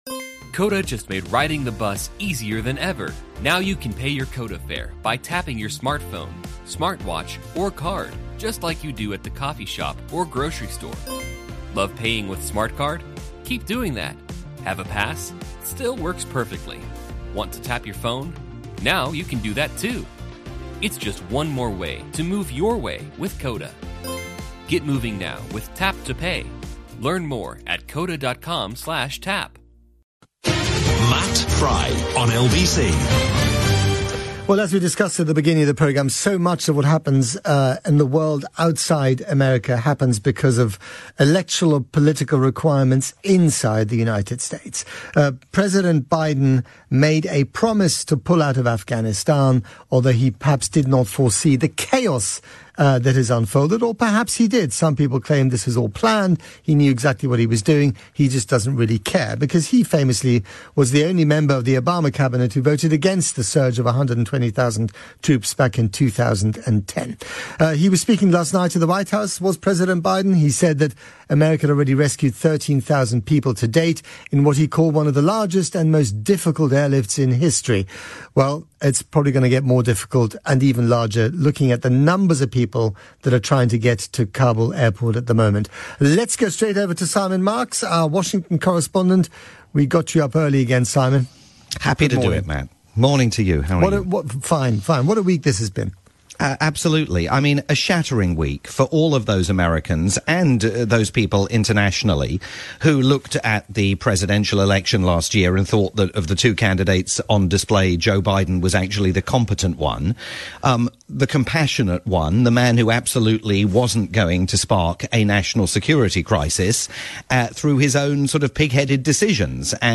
live update for Matt Frei's Saturday morning programme on the UK's LBC.